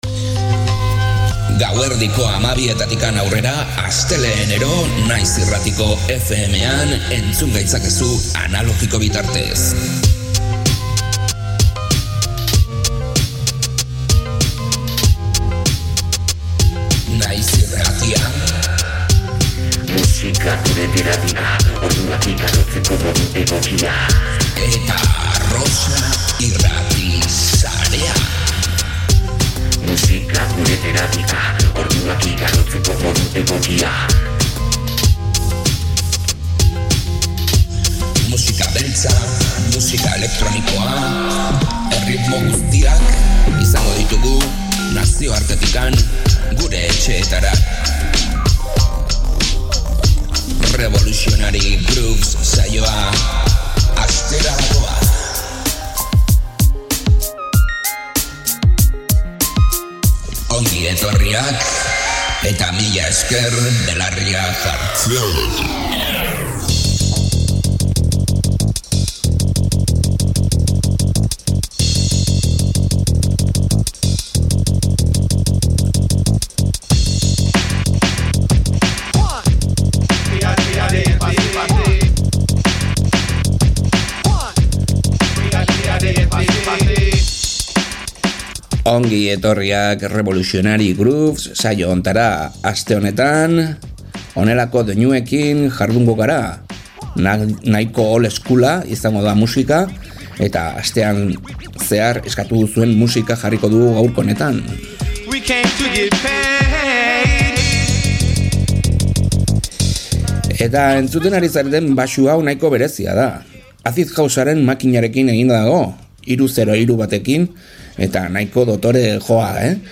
21. mendeko mashup eta erremix interesgarriak